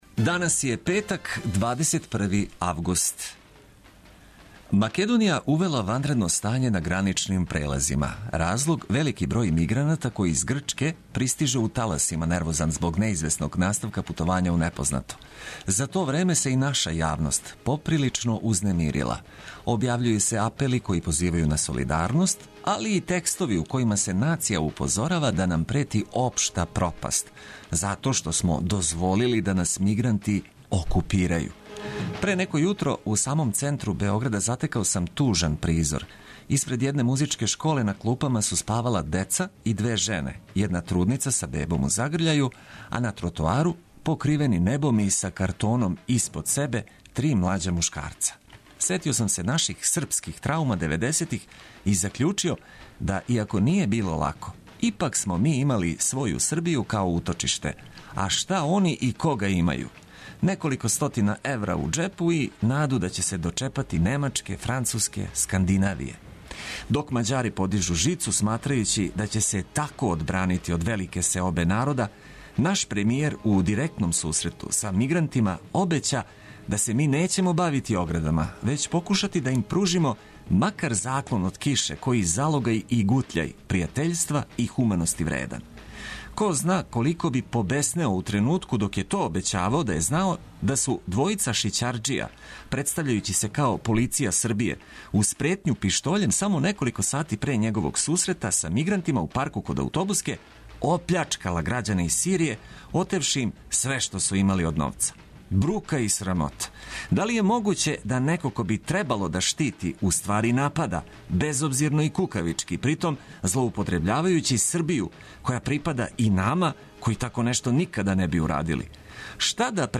Обиље информација уз добру музику - тако ћемо се будити током јутра тражећи мотив да устанемо а не одустанемо.